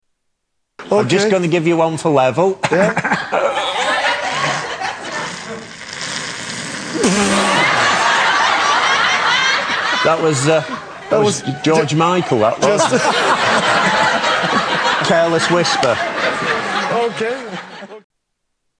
Fart Music